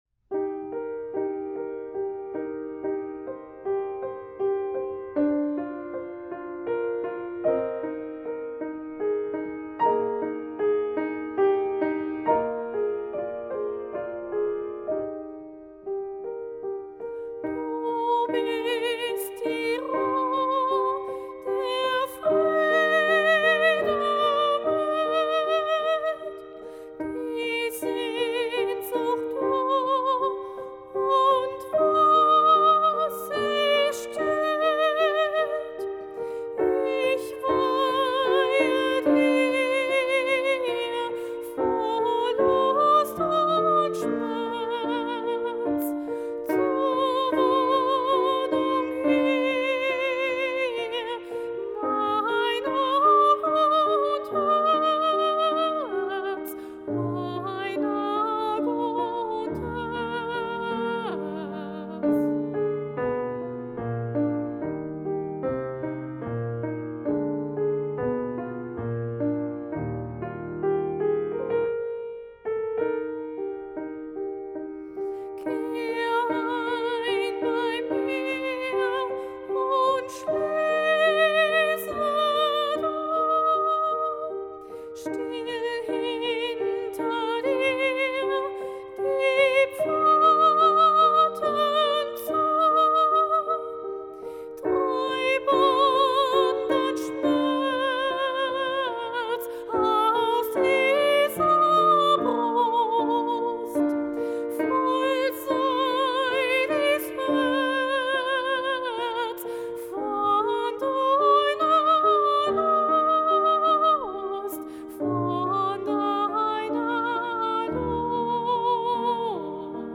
Sopranistin